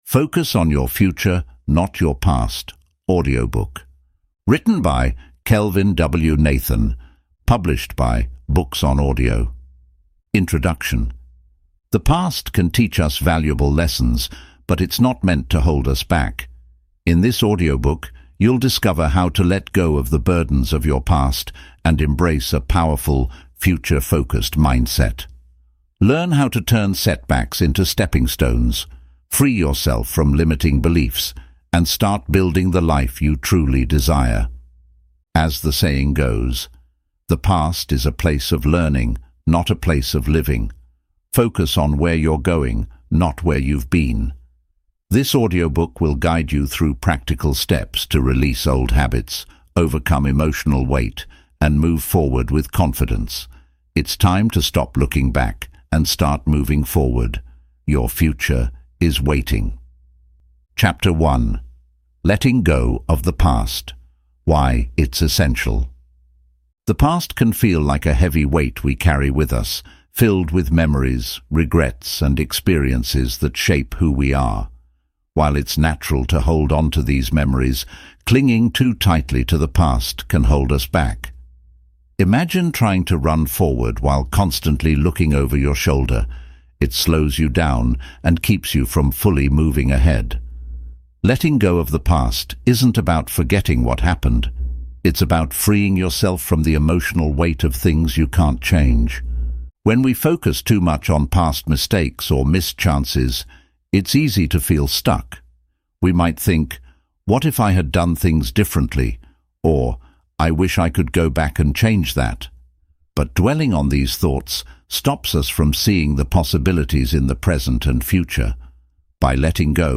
Focus on Your Future: Leave the Past Behind (Audiobook)